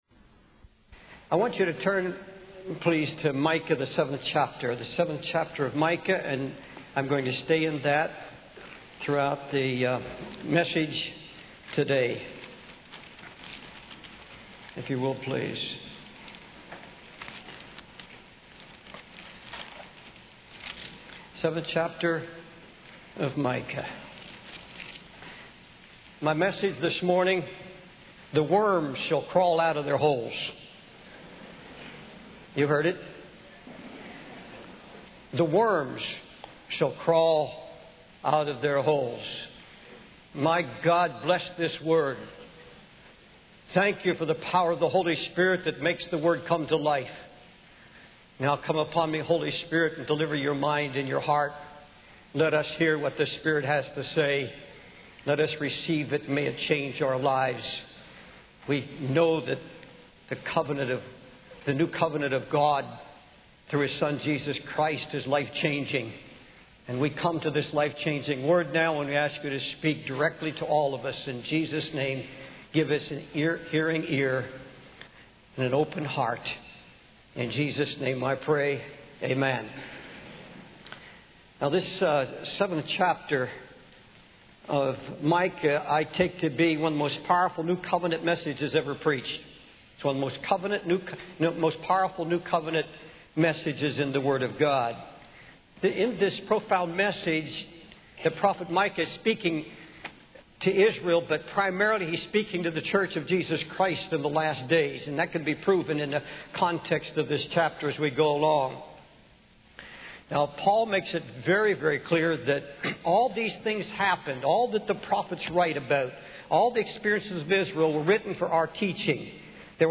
In this sermon, the speaker discusses the loss of trust and confidence in various institutions, including the judicial system, school system, and even marriage. He highlights the prevalence of scams and deceptions targeting the elderly, leading to their financial ruin and homelessness. The speaker also laments the decline in moral values and the watering down of the gospel in churches, with pastors avoiding the mention of sin and focusing on shallow messages.